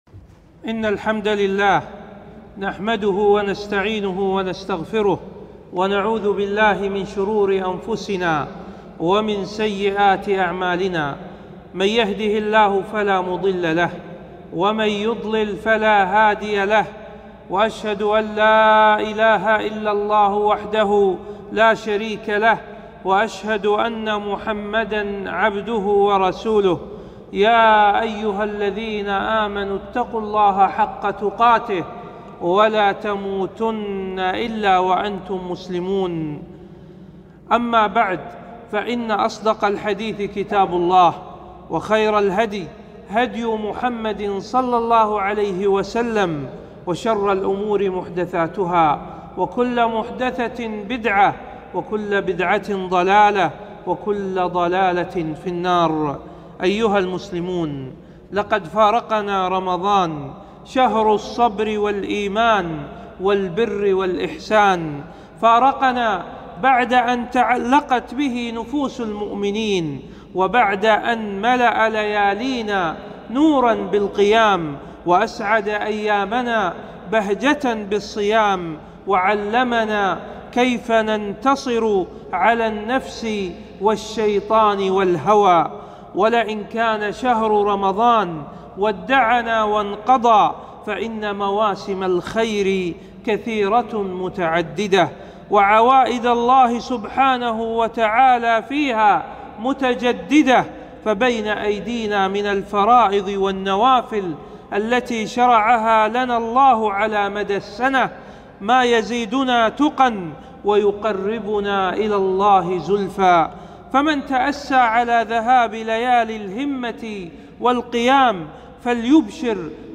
خطبة - الثبات على الطاعة بعد رمضان